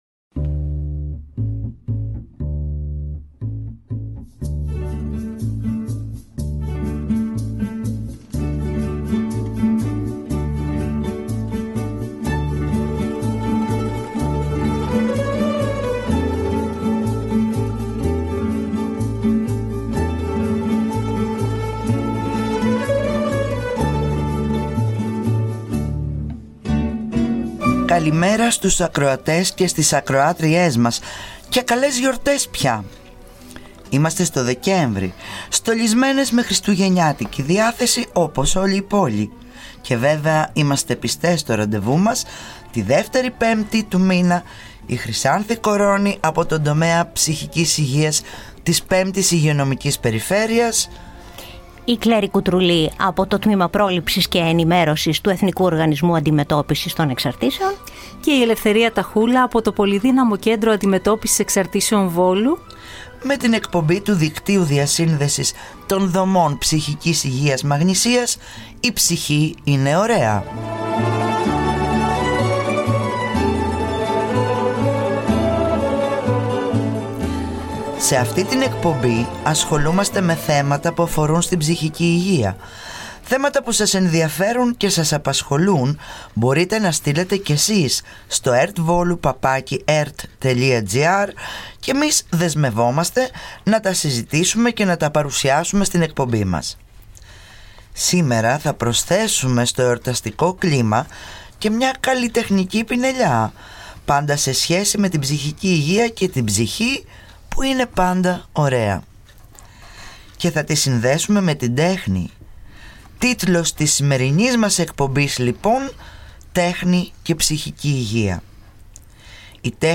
«Η ψυχή είναι ωραία!»: Ραδιοφωνική εκπομπή του Δικτύου Διασύνδεσης των δομών ψυχικής υγείας Μαγνησίας.
Παράλληλα, ακούγονται μικρά σχετικά αποσπάσματα από την λογοτεχνία και το θέατρο και προτείνονται βιβλία και ταινίες για τους αναγνώστες και κινηματογραφόφιλους ακροατές. ου αφορούν σε εξαρτήσεις.